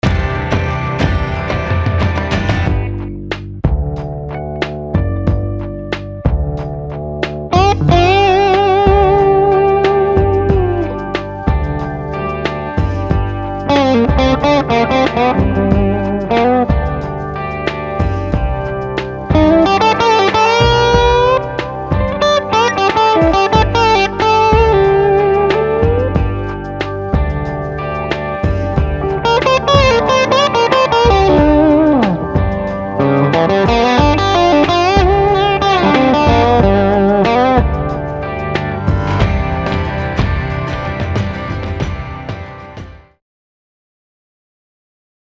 Der CT-HB-Vin klingt äußerst transparent und durchsichtig. Der Bassbereich tönt straff und sehnig, ganz ähnlich wie bei unserer Gibson-Referenz.
Auch dynamisch kann dieser Pickup zupacken und präsentiert einen knackigen, gut akzentuierten Anschlag, der in eine wunderbar komplexe Saitenschwingung mündet.
Hervorragend ist dagegen dieser unverkennbare Twang, der schon eher ans Gretsch-Lager erinnert. Durchsetzungsstark und wasserklar perlen die Noten aus dem Verstärker.